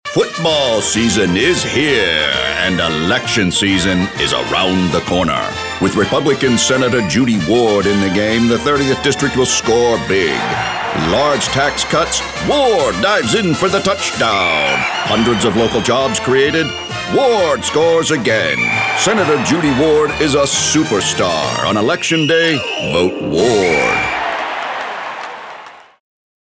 announcer, authoritative, Booming, Deep Voice, Gravitas, middle-age, political, retro